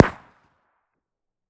Cookoff - Improve ammo detonation sounds